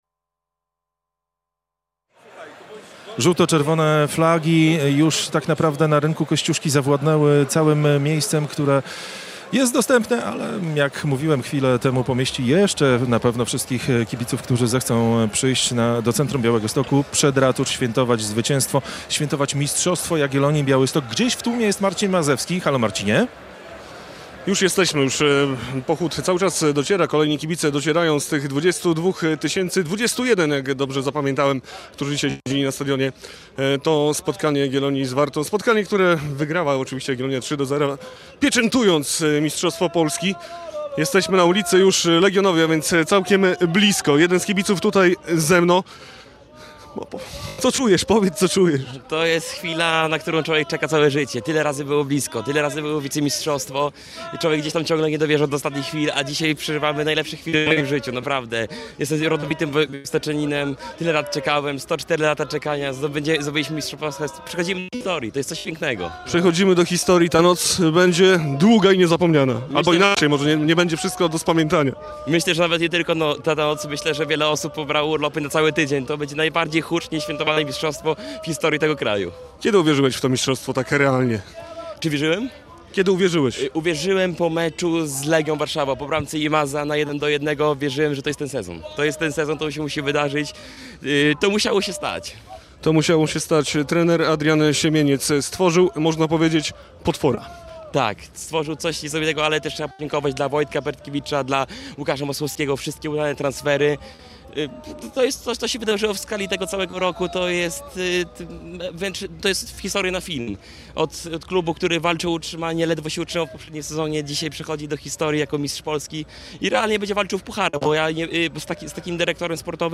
rozmawia z kibicami Jagiellonii